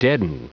Prononciation du mot : deaden